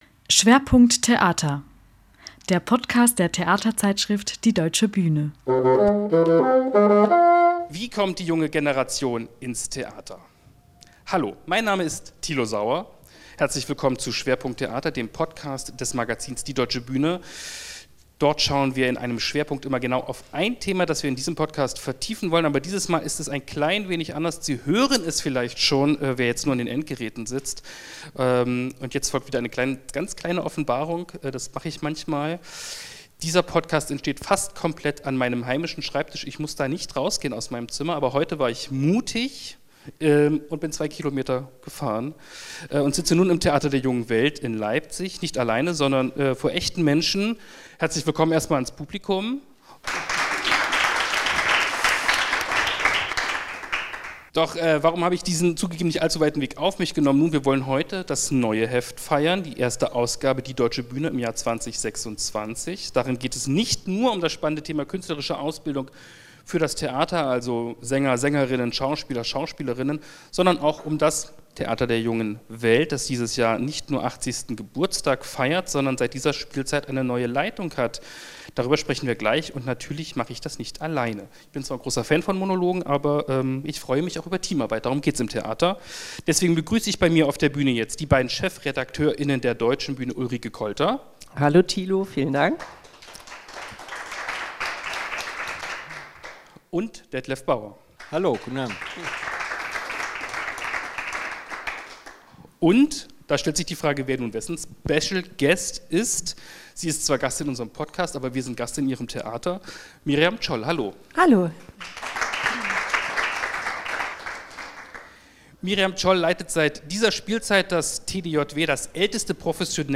Live-Podcast „Schwerpunkt Theater“
Zum ersten Mal entstand der Podcast „Schwerpunkt Theater“ live